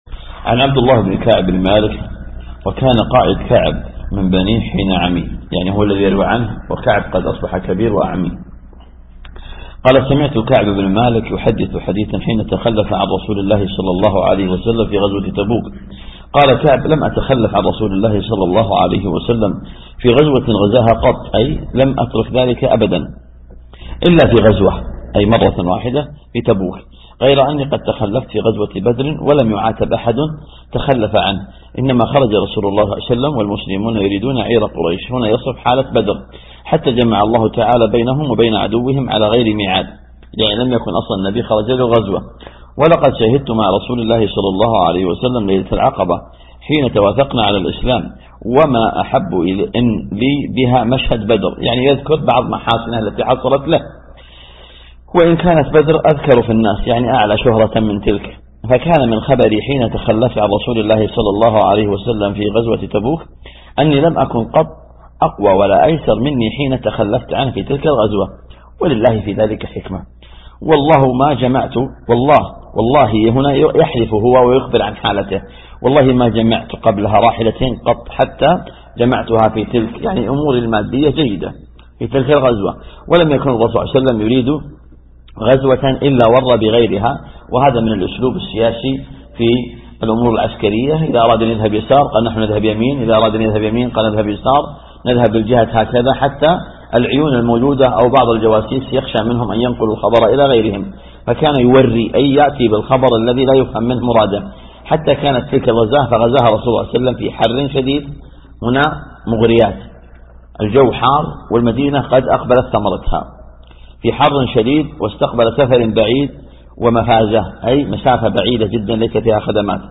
محاضرة يا باغي الخير أقبل